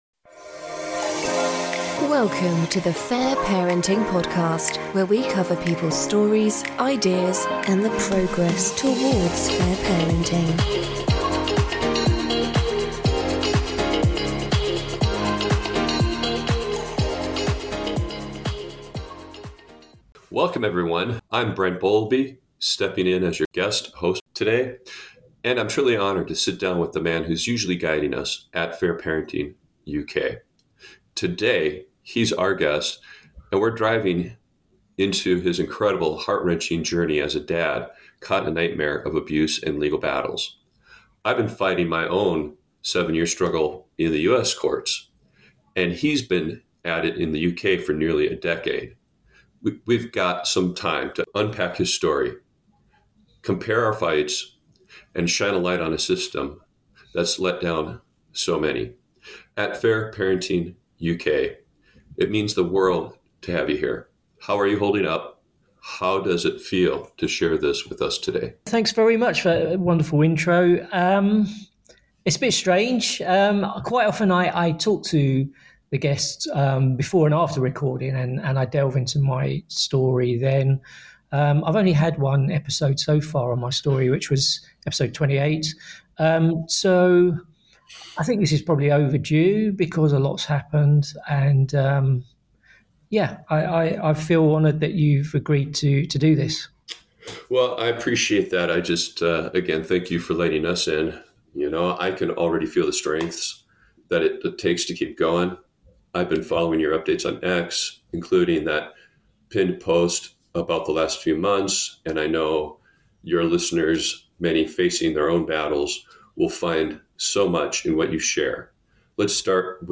Story: An Interview Updating My Own Story (Part 1)